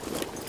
Gear Rustle Redone
tac_gear_21.ogg